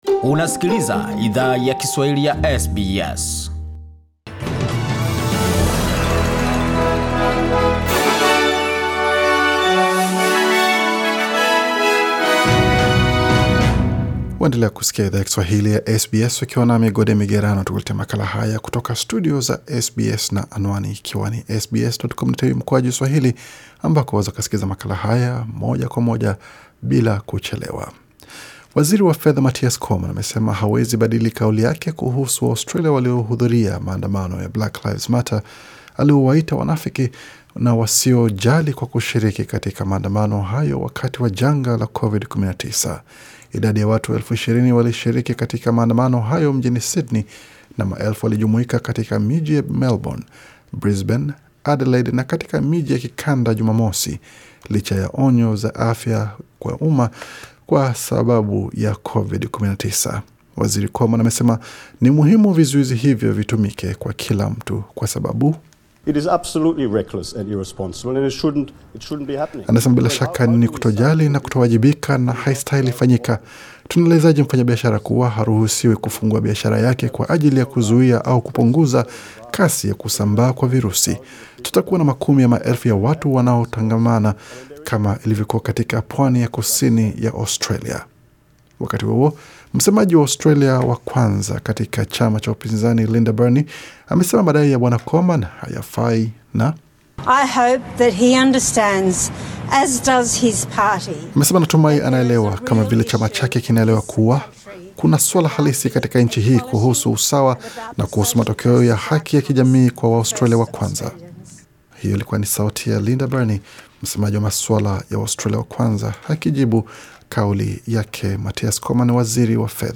Taarifa ya habari 7 Juni 2020